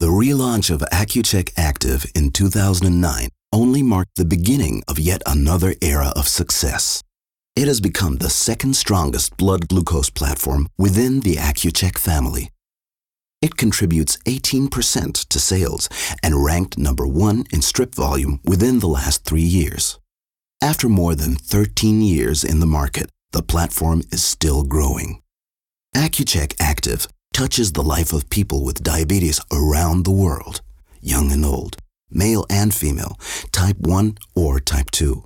mid-atlantic
Sprechprobe: Industrie (Muttersprache):
The warm but serious tone of his voice lends itself to many situations, where an American is required to speak in a " European" context.